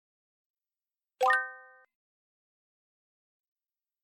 Play, download and share CSG alert 1 original sound button!!!!